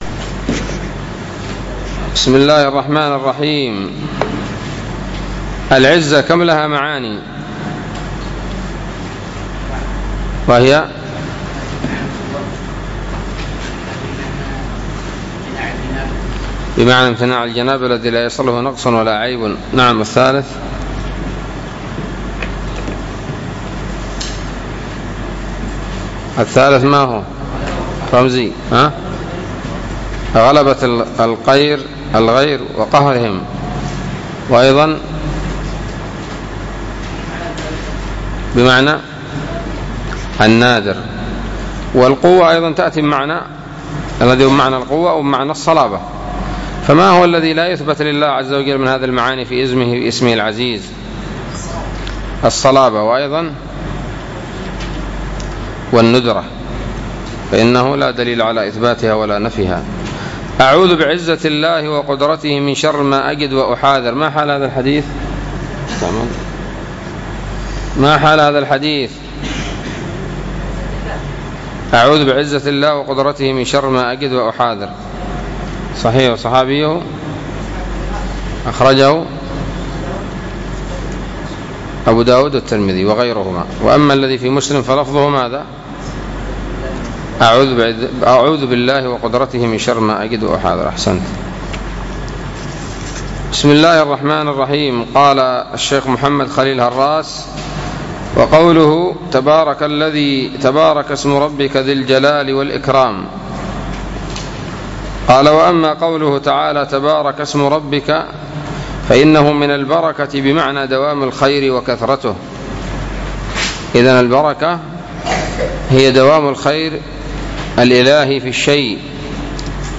الدرس الثامن والستون من شرح العقيدة الواسطية